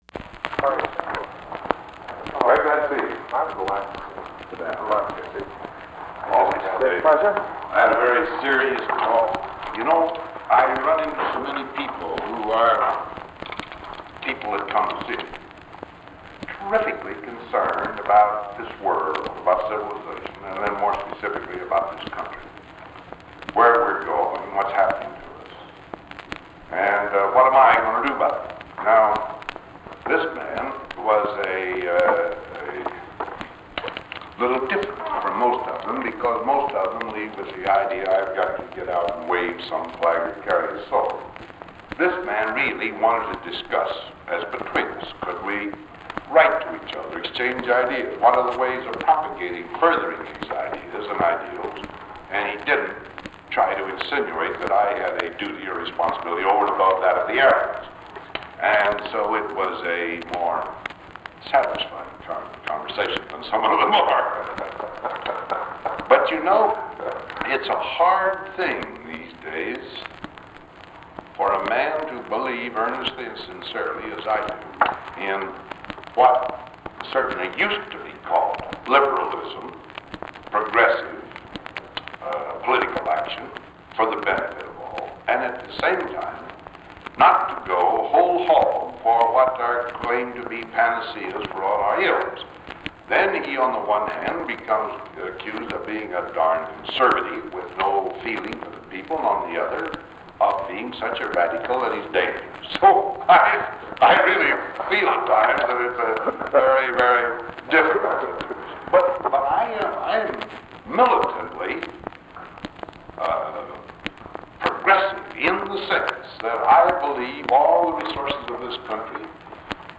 Recording begins as Eisenhower is talking about other people's assumption that he must take a leadership role in this country.
Secret White House Tapes